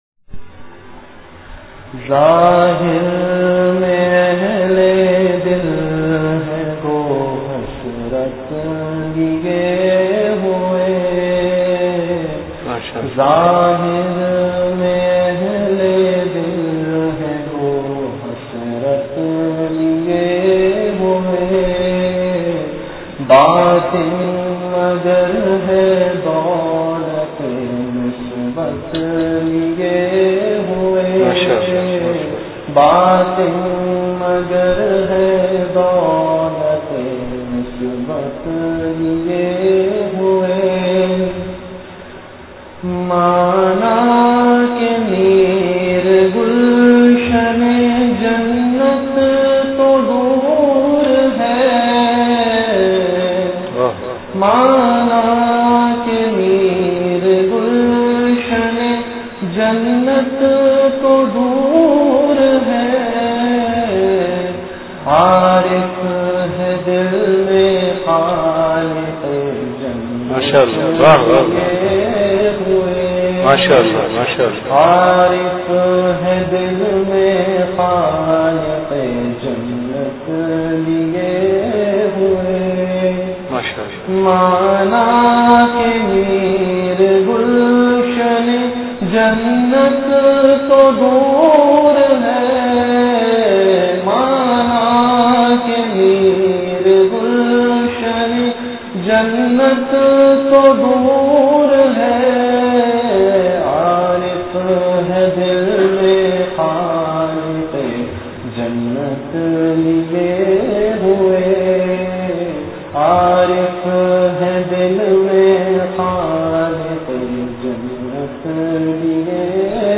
Delivered at Home.
Category Majlis-e-Zikr
Event / Time After Isha Prayer